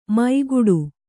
♪ maiguḍu